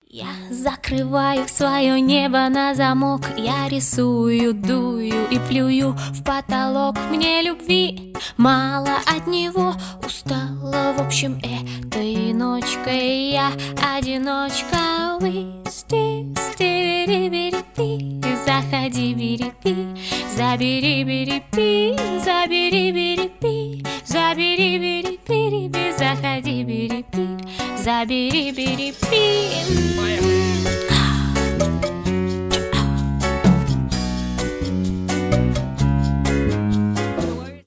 акустика
поп